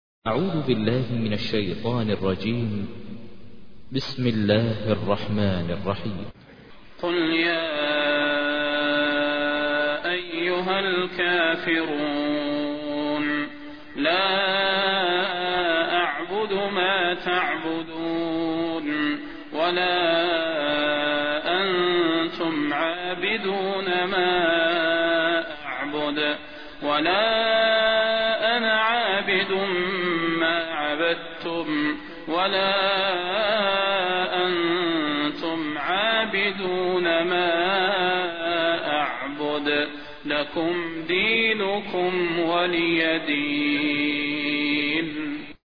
تحميل : 109. سورة الكافرون / القارئ ماهر المعيقلي / القرآن الكريم / موقع يا حسين